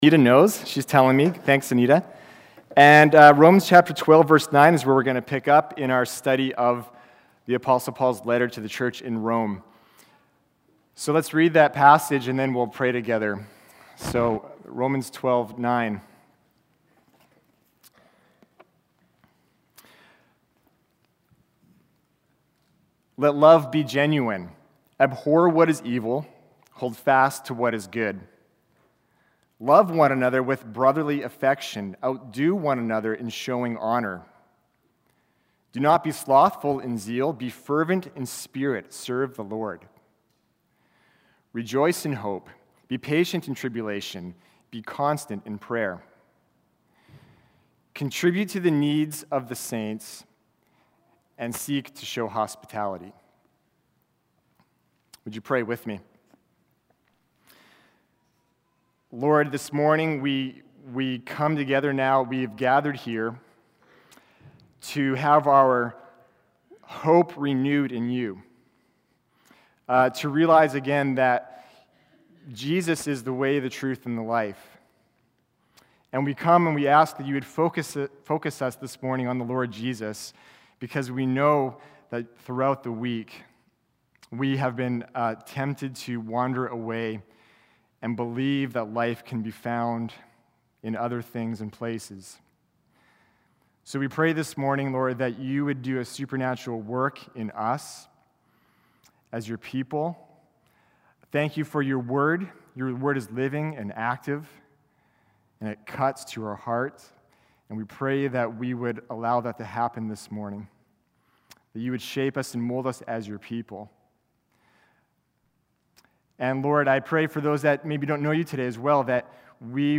Sermons | Grace Baptist Church